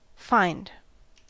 Ääntäminen
Ääntäminen US Haettu sana löytyi näillä lähdekielillä: englanti Käännöksiä ei löytynyt valitulle kohdekielelle. Fined on sanan fine partisiipin perfekti.